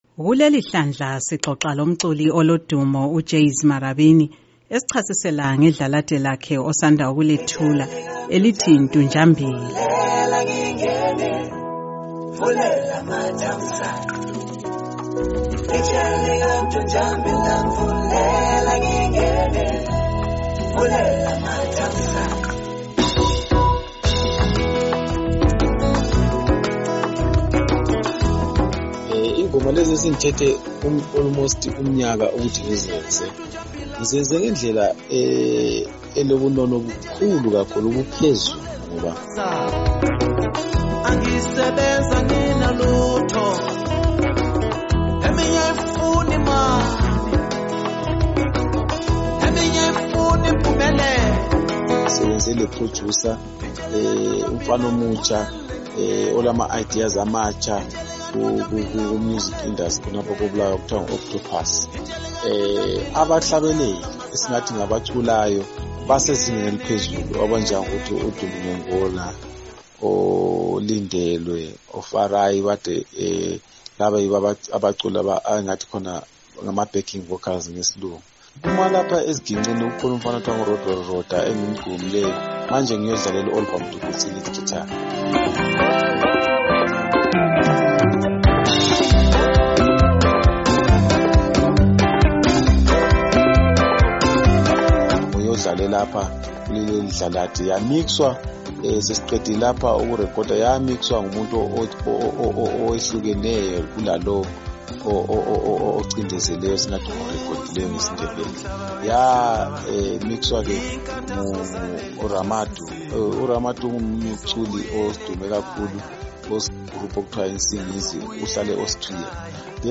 Ingxoxo loJeys Marabini